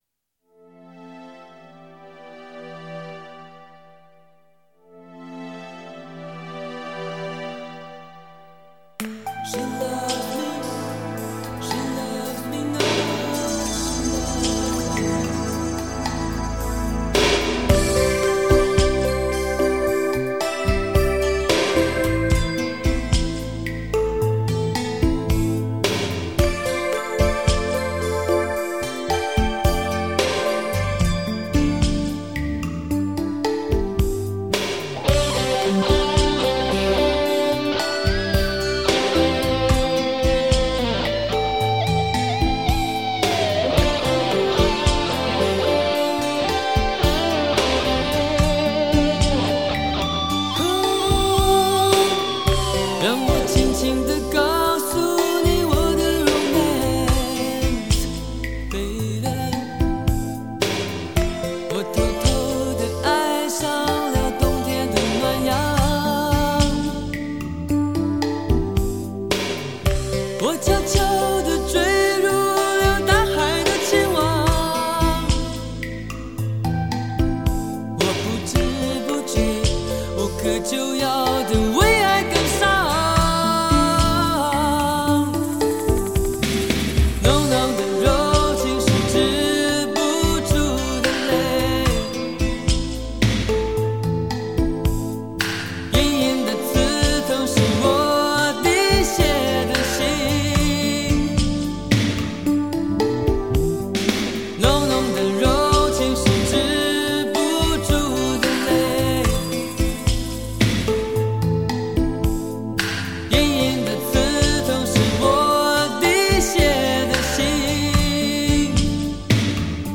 声音干净漂亮，深情款款。